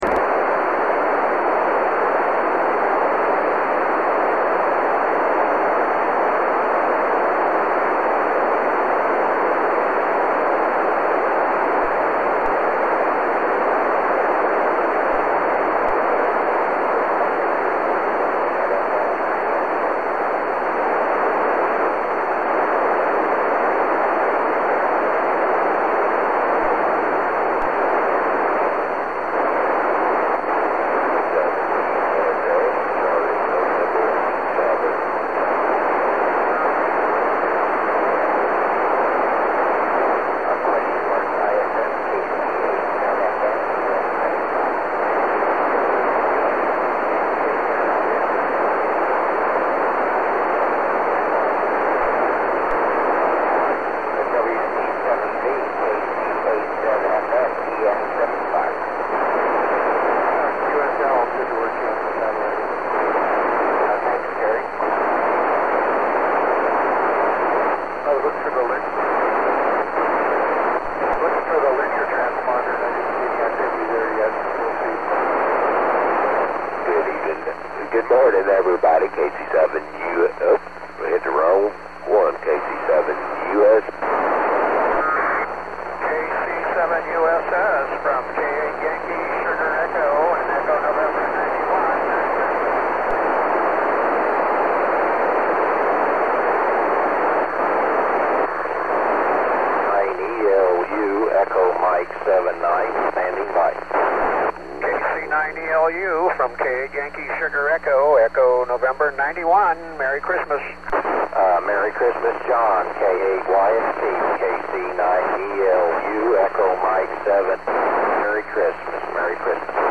How the two work together is not obvious but it does seem that the packet shuts down the voice repeater for a period of time. What makes the voice repeater start up again is not clear.